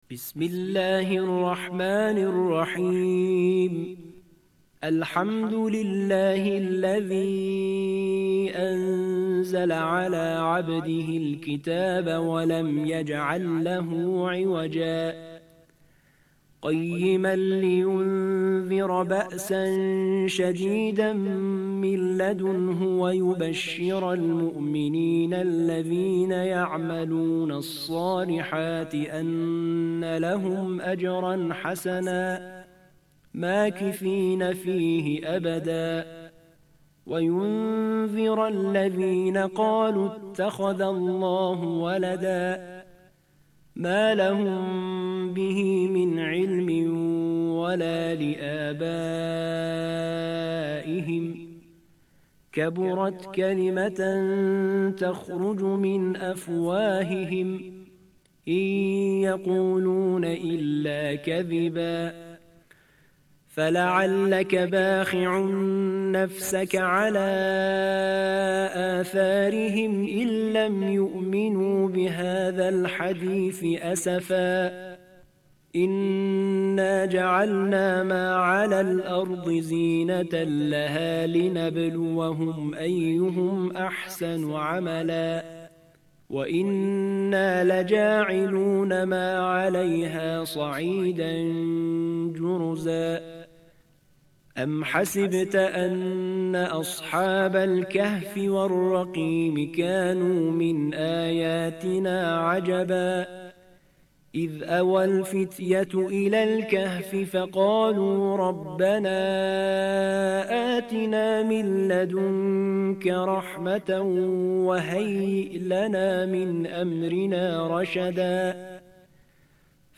تلاوت مجلسی سوره مبارکه کهف آیات ۱-۷۴